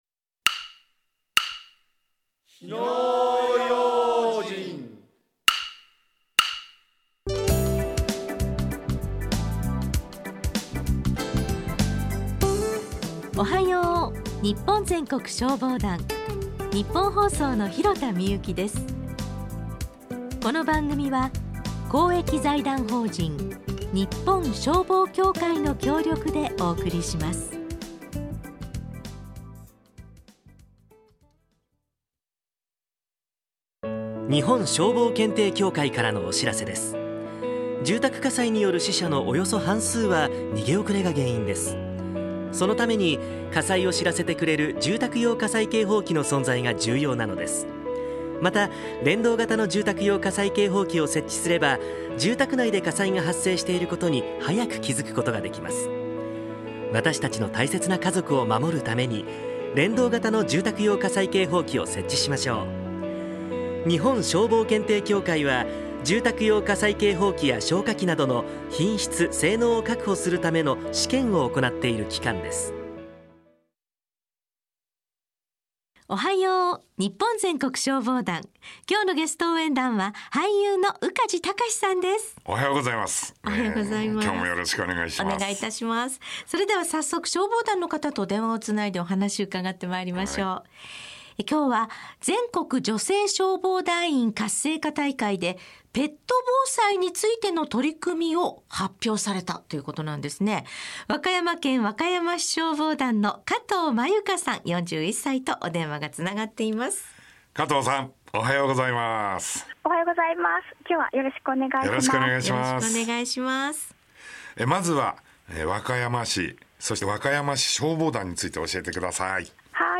ラジオ放送
収録では、優しく相槌をうってくださり自然と緊張がほぐれました。